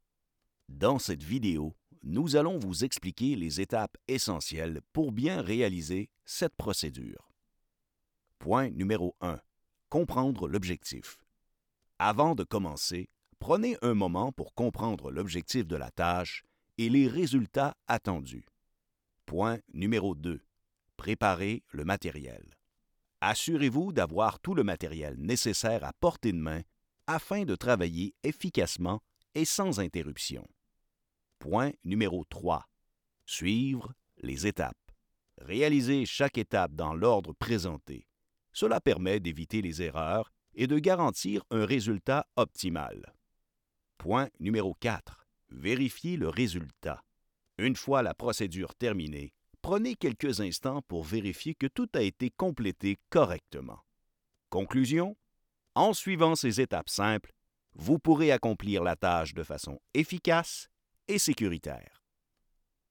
Francés (Canadá)
Profundo, Seguro, Maduro
Audioguía